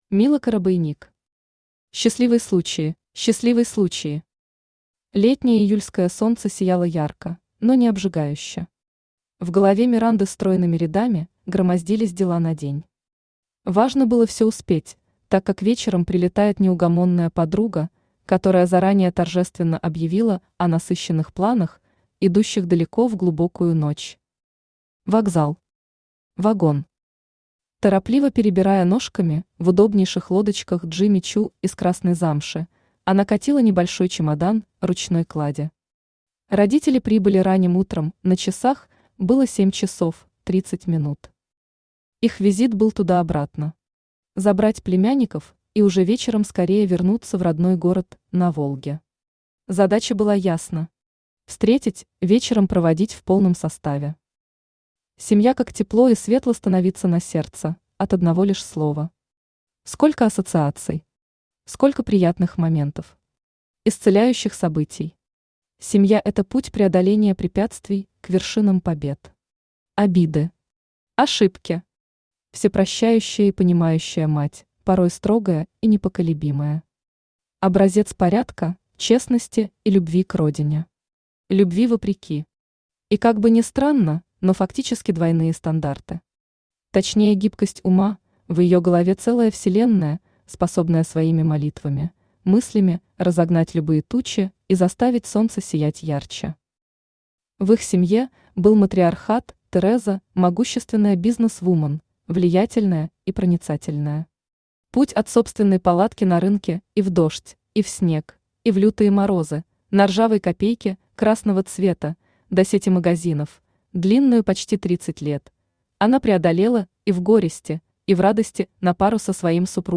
Аудиокнига Счастливый случай | Библиотека аудиокниг
Aудиокнига Счастливый случай Автор Mila Korobeynik Читает аудиокнигу Авточтец ЛитРес.